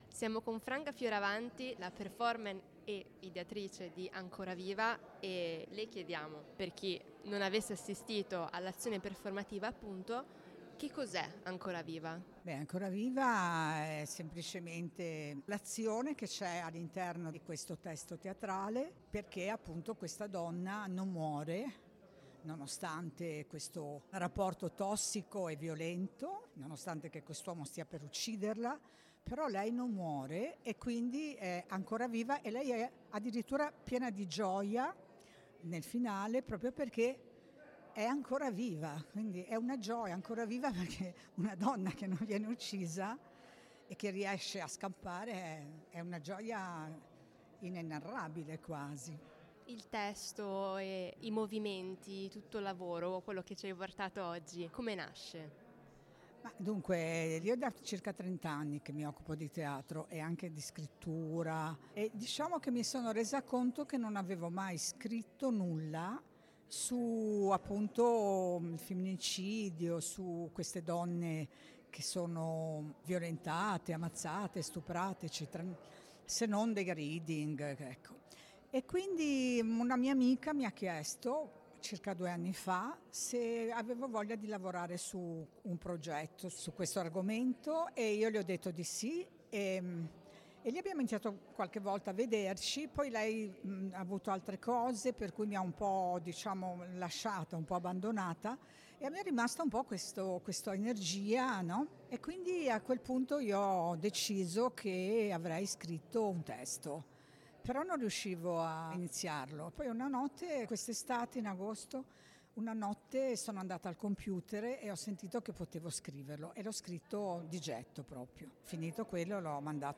In questa intensa intervista